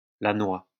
[lanwa](info).